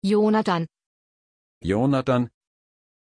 Pronunciación de Joonatan
pronunciation-joonatan-de.mp3